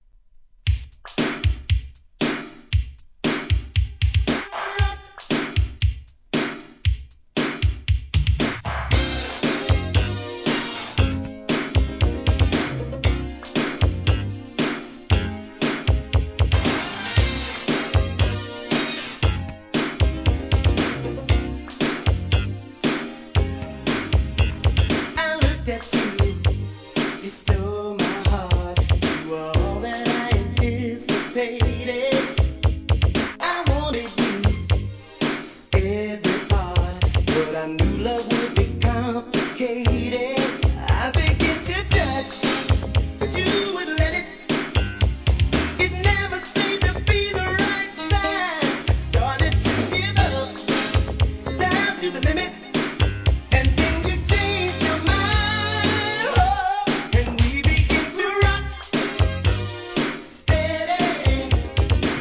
¥ 660 税込 関連カテゴリ SOUL/FUNK/etc...